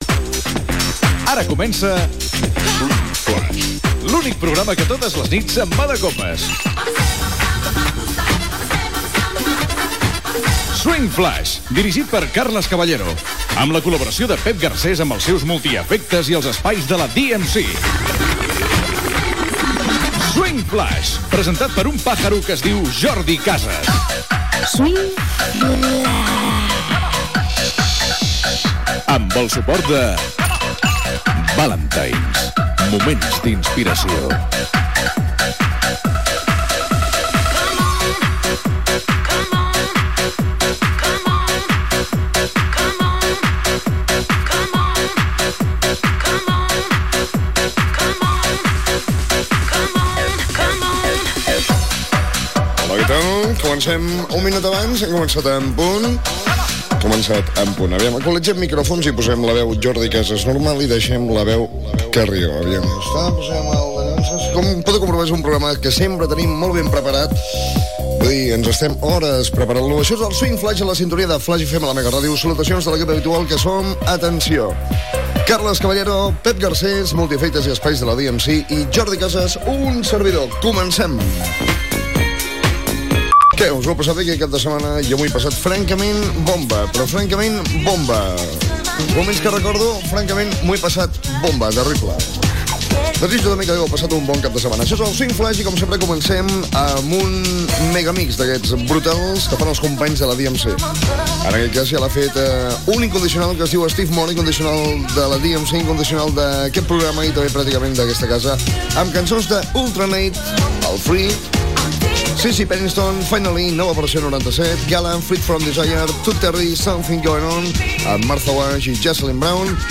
Introducció, presentació de l'equip del programa, presentació de temes musicals, mencions publicitàries i indicatius del programa.
Musical